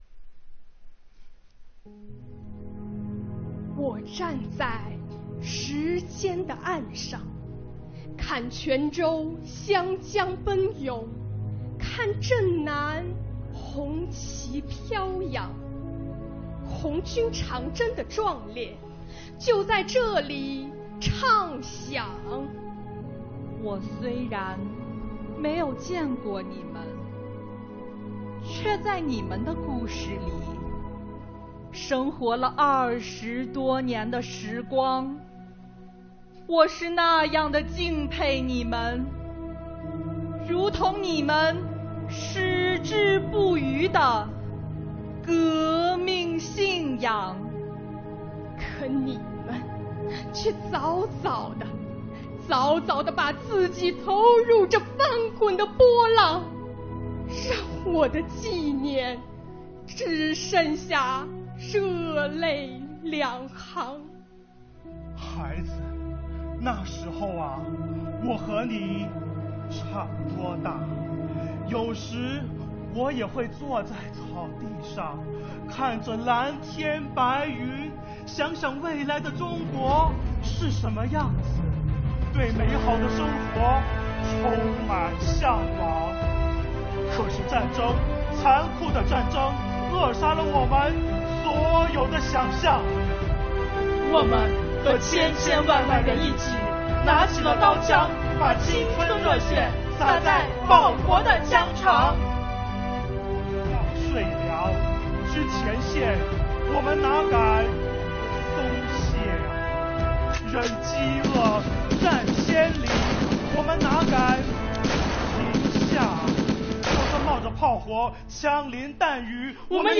2022年7月21日，桂林市“喜迎党的二十大 踔厉奋发向未来”第十四届“我邀明月颂中华”爱国诗词诵读大赛决赛在桂林市广播电视台举行，桂林国家高新技术产业开发区税务局代表桂林市税务系统参加了本次比赛。
忍饥饿，战千里，我们哪敢停下啊……”比赛现场，桂林国家高新技术产业开发区税务局参赛选手们声情并茂、富有感染力的表演，获得评委和观众的好评。“